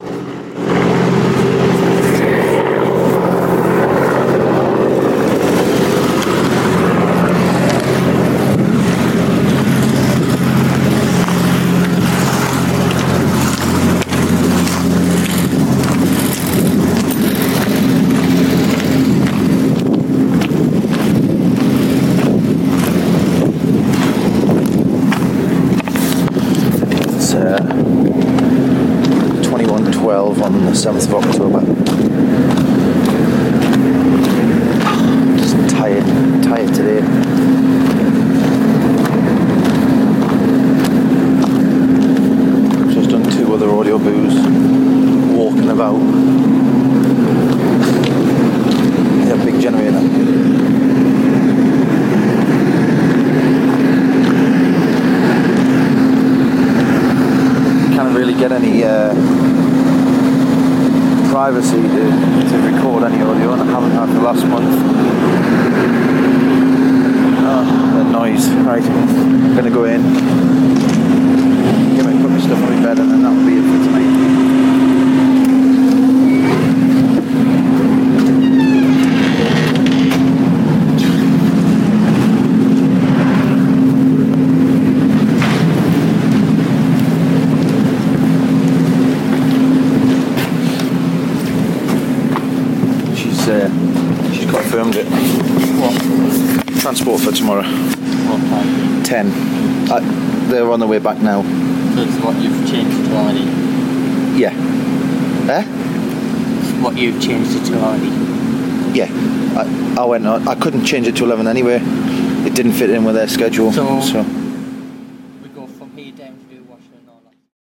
Walking back to accommodation from coffee shop [soundscape with narration]